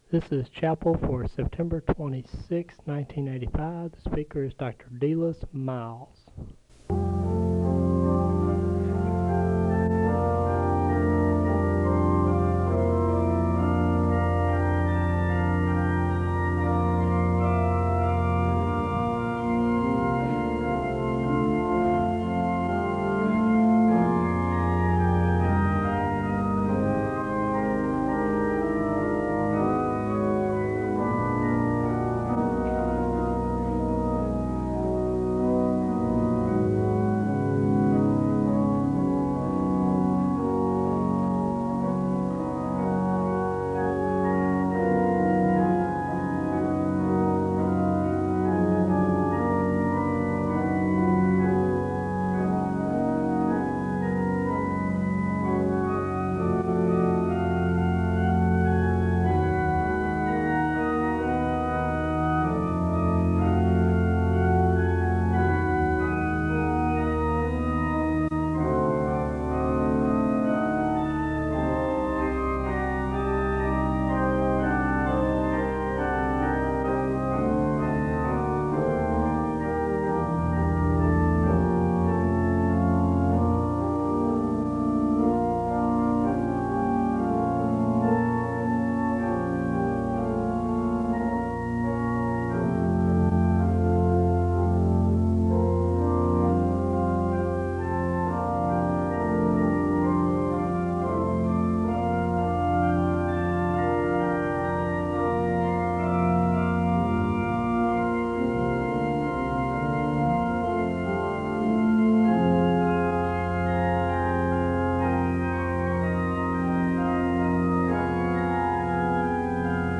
The service begins with organ music (0:00-2:42). There is a moment of prayer (2:43-4:02). The choir sings an anthem (4:03-9:39).